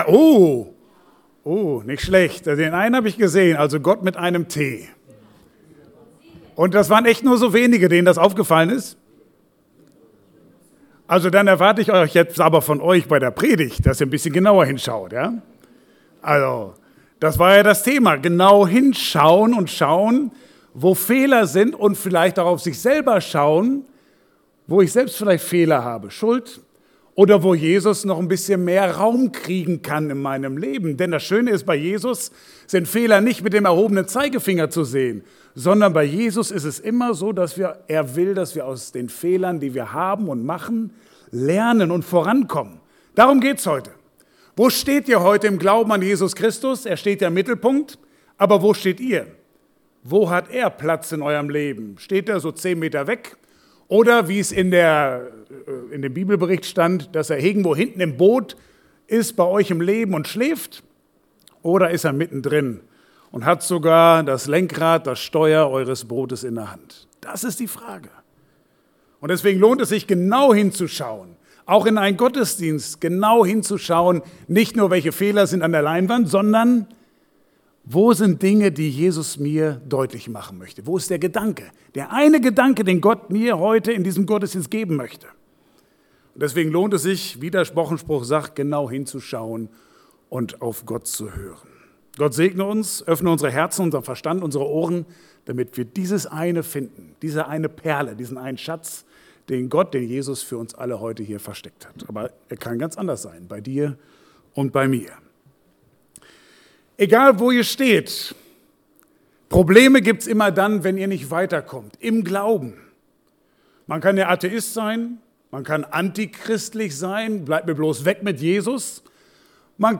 Passage: Markus 4, 35-41 Dienstart: Gottesdienst « Initialzündung Was fange ich mit meiner Zeit an